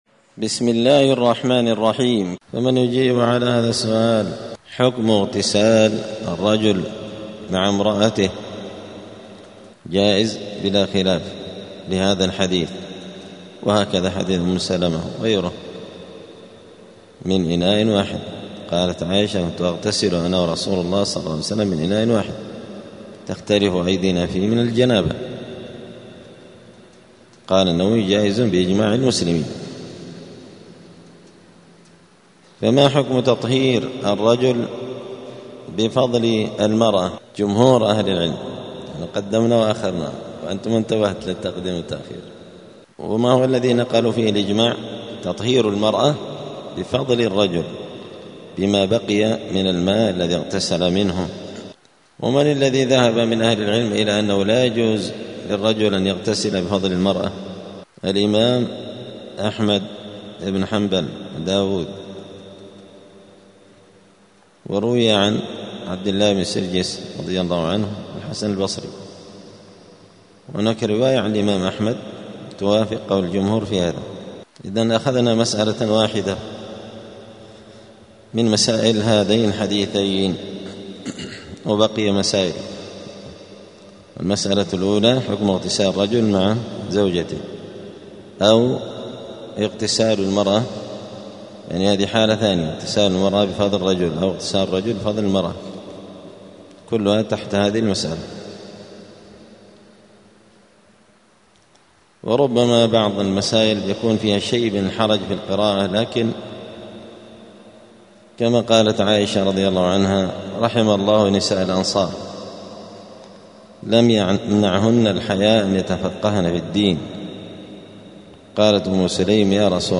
دار الحديث السلفية بمسجد الفرقان قشن المهرة اليمن
*الدرس الخامس والتسعون [95] {باب الحيض حكم مباشرة الحائض}*
95الدرس-الخامس-والتسعون-من-كتاب-بغية-الحافظين-كتاب-الطهارة.mp3